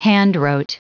Prononciation du mot handwrote en anglais (fichier audio)
Prononciation du mot : handwrote